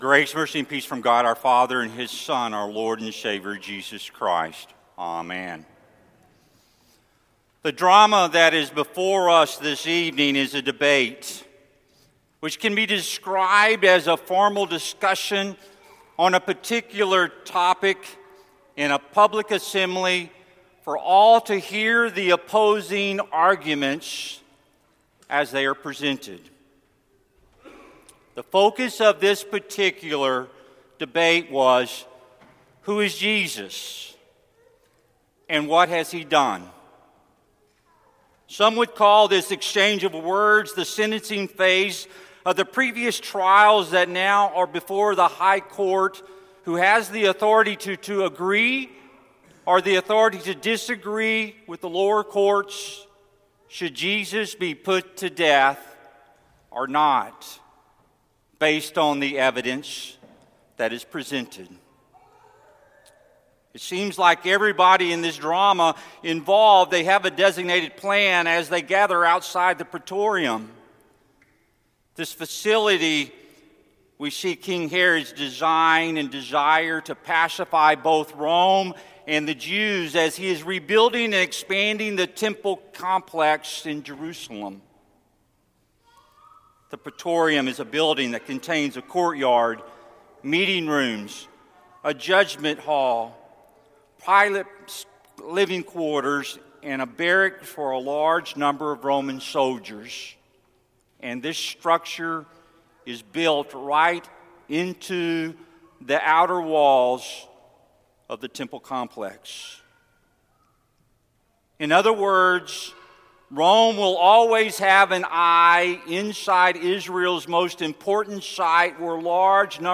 Sermon for Lent Midweek 4